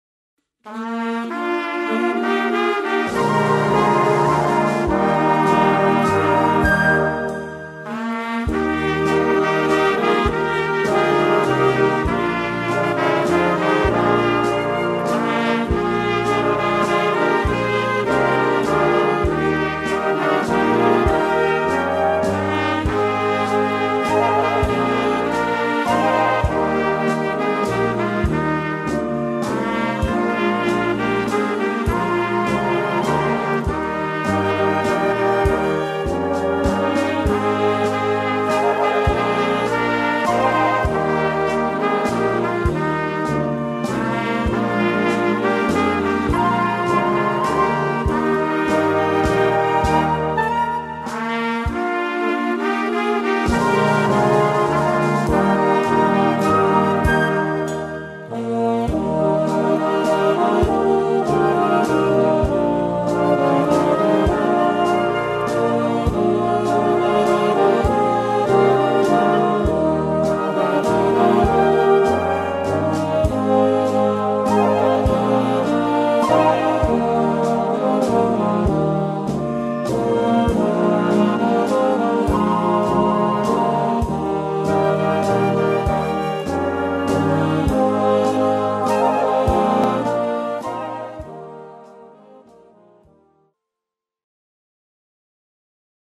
Gattung: Volkslied
2:45 Minuten Besetzung: Blasorchester Tonprobe